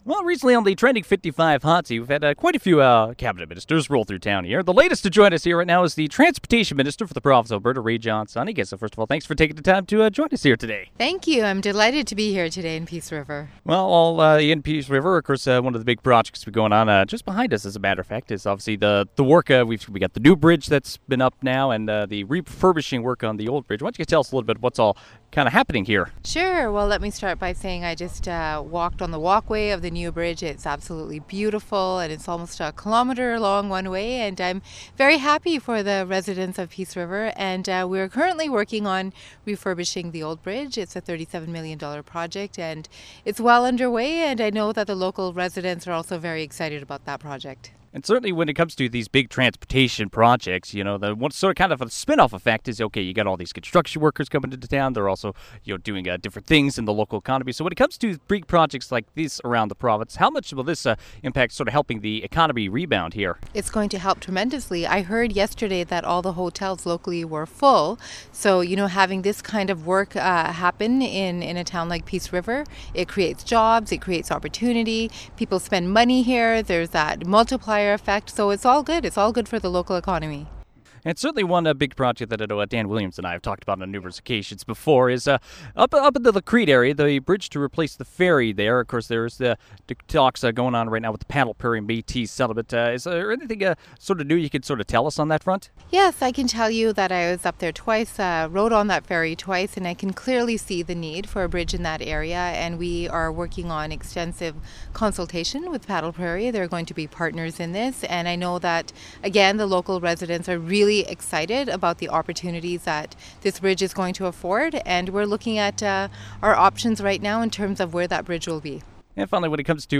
You can hear our full interview with Sawhney below.